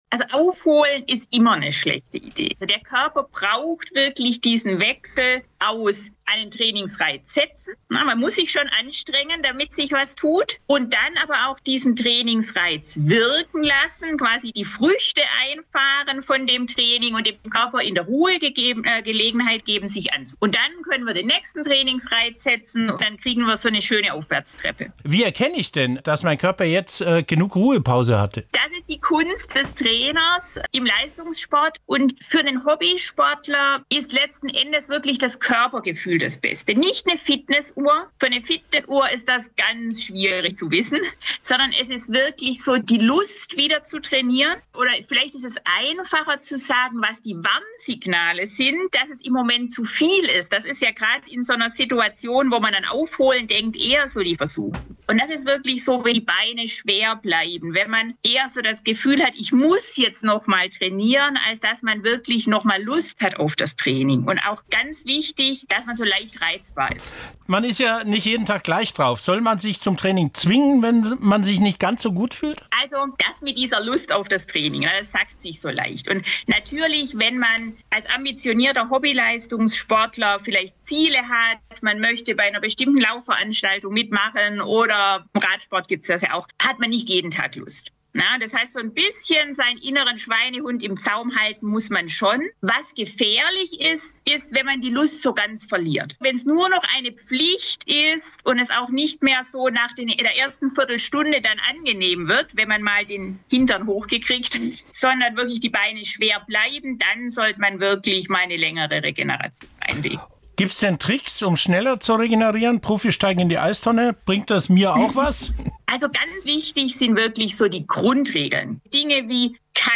Sportmedizinerin